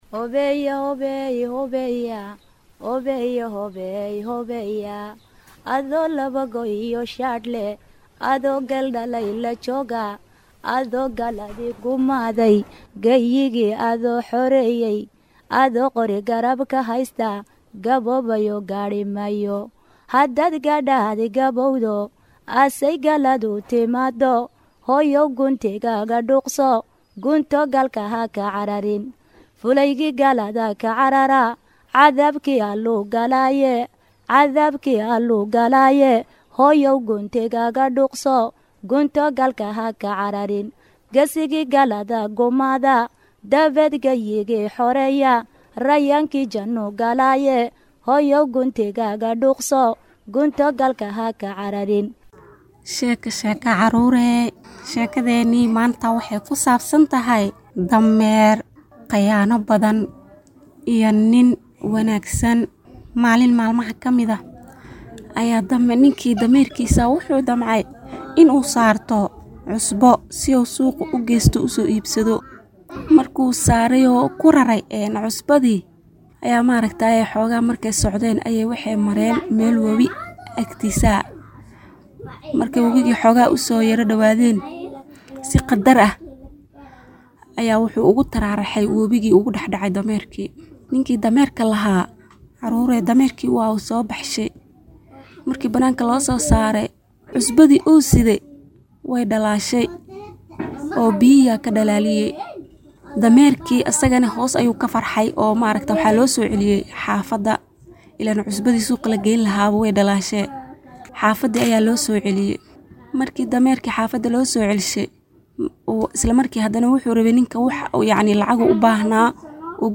Barnaamijka Tarbiyada Caruurta ee Jimco weliba ka baxa warbaahinta Islaamiga ah ee Al-Furqaan, waa barnaamij tarbiyo iyo barbaarin oo ku socda caruurta iyo waalidiintooda, waxaana xubnaha ku baxa kamid ah xubin loogu magacdaray Sheeko-Xariir, oo ay soo jeedinayaan hooyooyinka Soomaaliyeed.